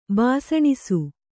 ♪ bāsaṇisu